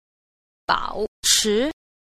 8. 保持 – bǎochí – bảo trì